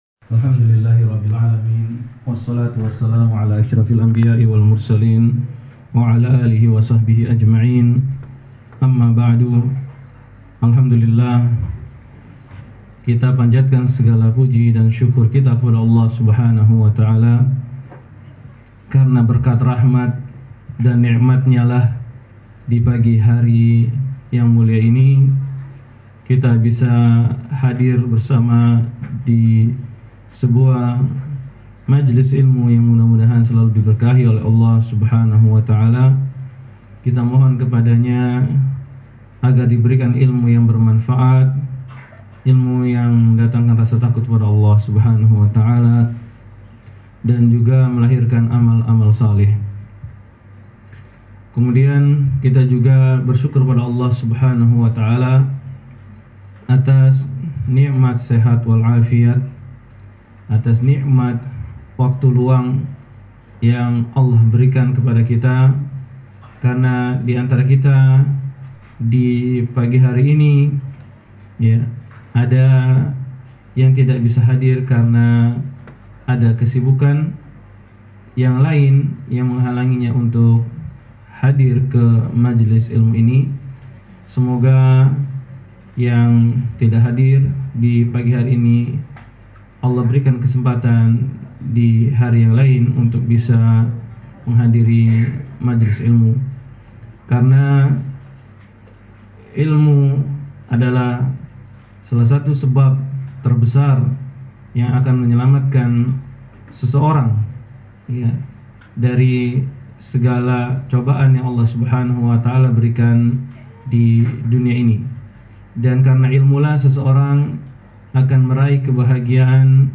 Daurah Umsaeed Jum’at Pagi